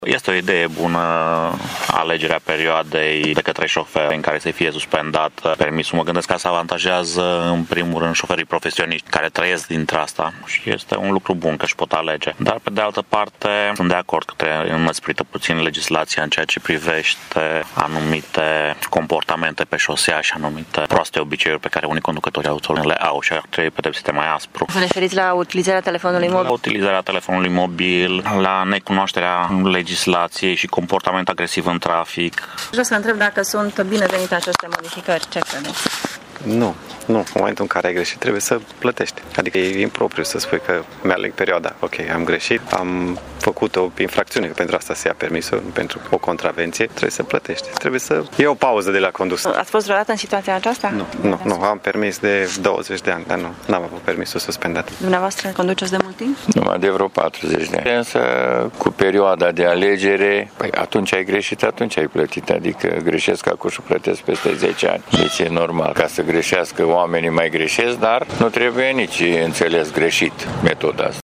Cel puțin o parte dintre șoferii întâlniți azi de reporterul Radio Tg.Mureș consideră că ar trebui înăsprite pedepsele pentru cei care încalcă regulile de circulație, și nu relaxate.
Legea îi va avantaja pe șoferii profesioniști, însă pentru greșeli trebuie plătit imediat, cred șoferii târgumureșeni: